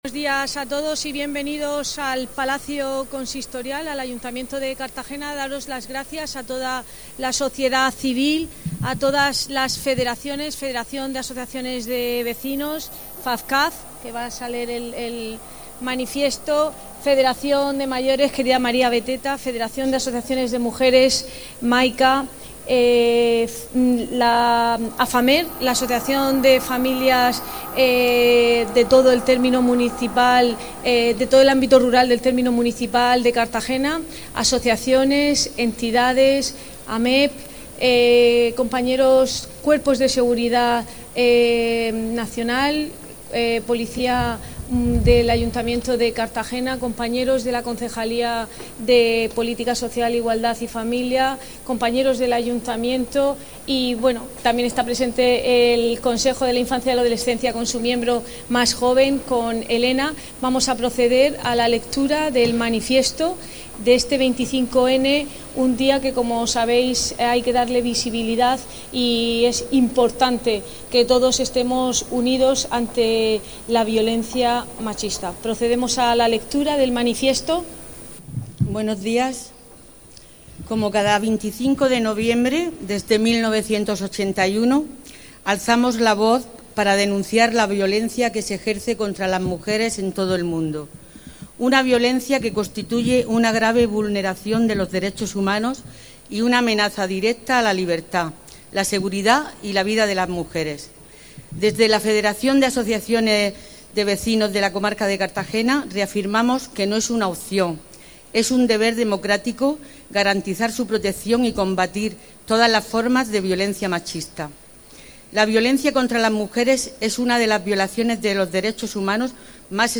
La alcaldesa de Cartagena, Noelia Arroyo, ha presidido este martes el acto conmemorativo con motivo del 25N, Día Internacional de la Eliminación de la Violencia contra la Mujer, en la Plaza del Ayuntamiento.
Además de la lectura del manifiesto, un minuto de silencio y la declaración institucional, uno de los momentos más emotivos del acto ha sido el gesto simbólico en recuerdo de las mujeres y niños asesinados en lo que llevamos de año, dos de las víctimas con residencia en Cartagena.